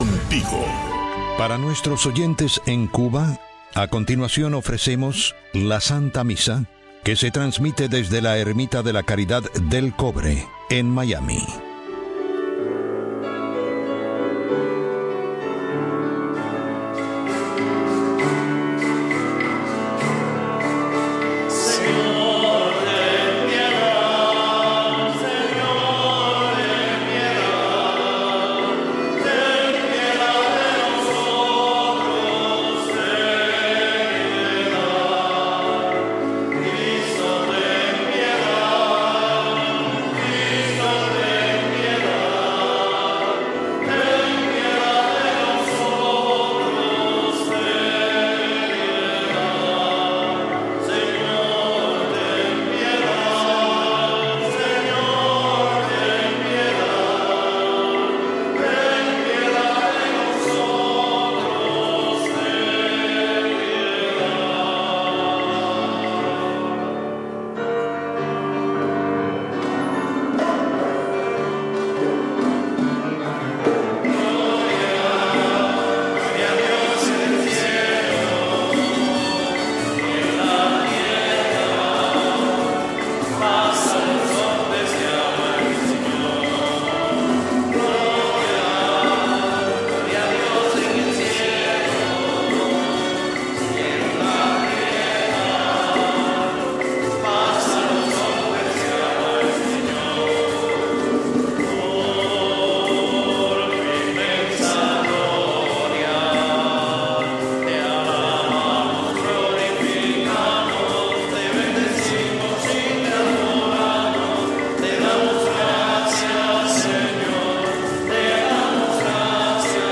La Santa Misa
La misa dominical transmitida para Cuba desde el Santuario Nacional de Nuestra Señor de la Caridad, un templo católico de la Arquidiócesis de Miami dedicado a la Patrona de Cuba.